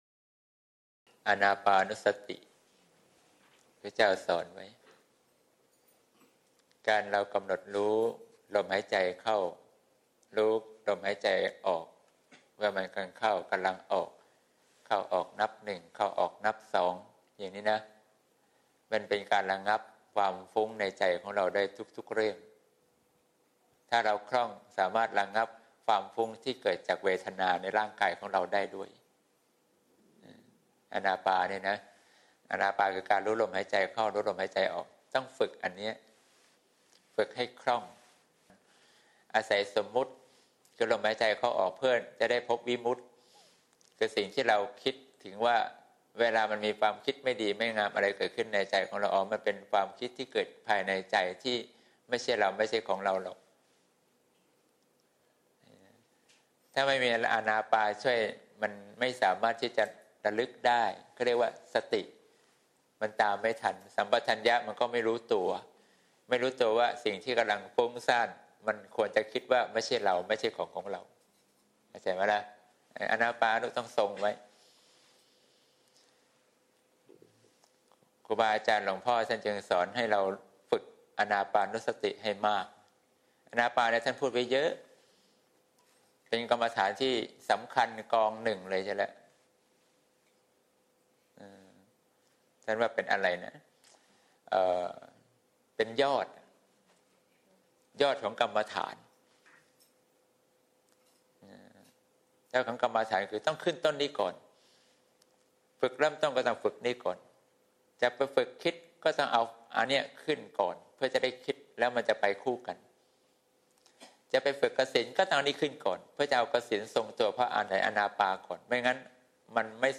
เสียงธรรม ๒๙ ธ.ค. ๖๗